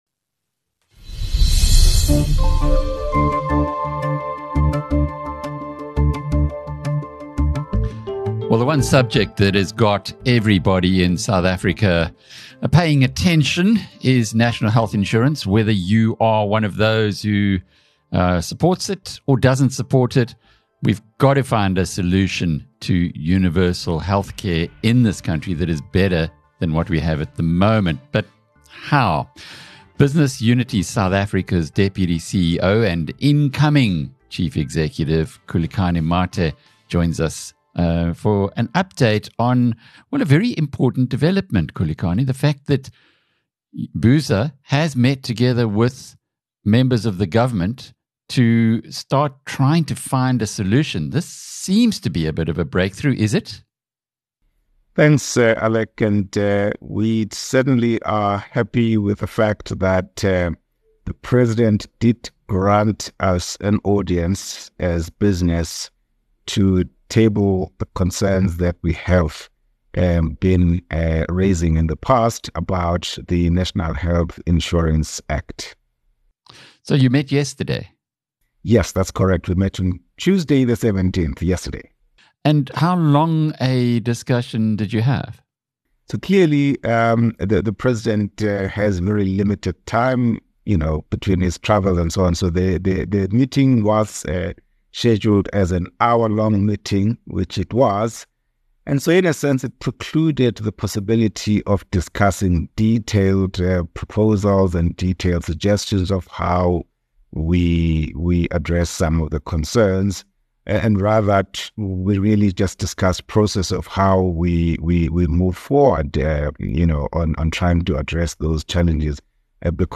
In a candid conversation